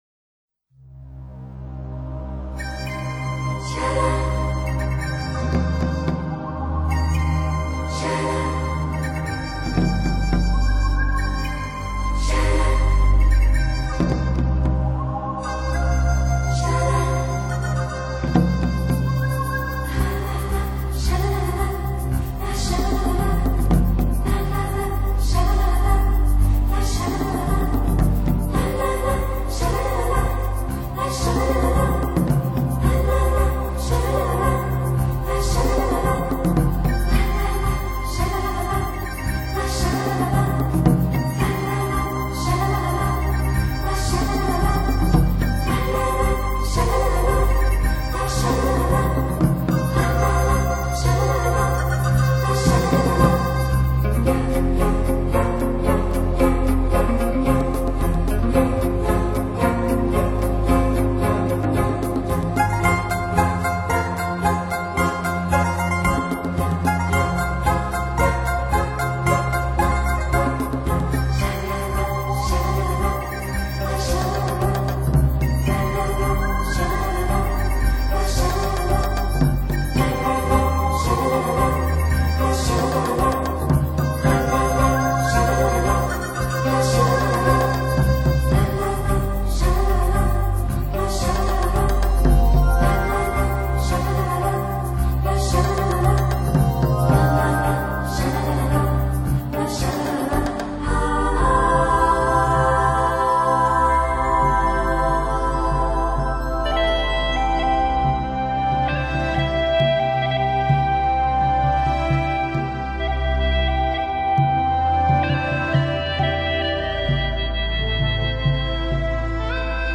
专辑语言：纯音乐